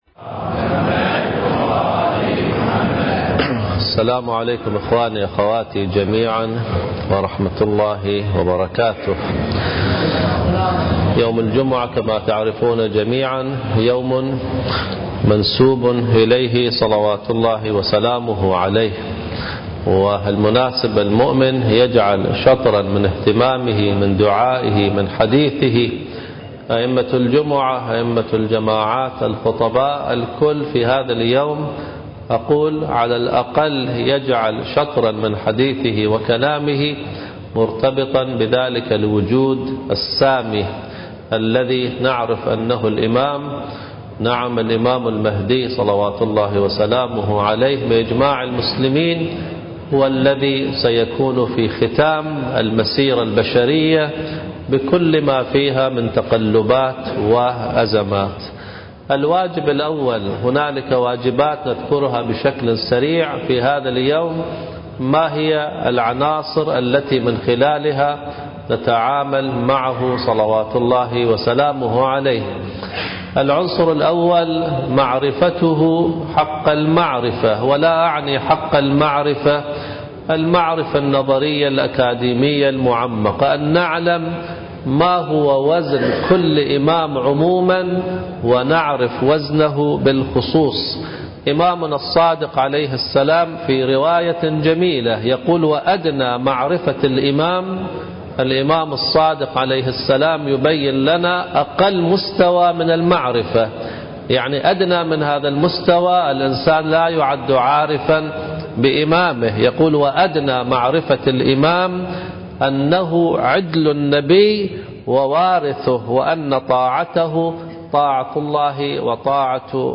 واجباتنا في زمن الغيبة (1) حديث الجمعة من خطبة الجمعة